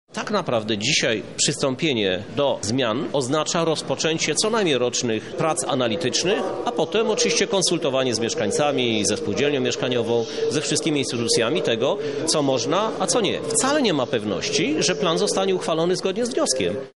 Tak na prawdę jeszcze nic nie jest przesądzone. O tym mówi Krzysztof Żuk, prezydent Lublina